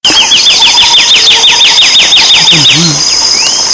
Arañero Ribereño (Myiothlypis rivularis)
Nombre en inglés: Riverbank Warbler
Localidad o área protegida: Reserva Privada y Ecolodge Surucuá
Condición: Silvestre
Certeza: Observada, Vocalización Grabada
Aranero-Ribereno.mp3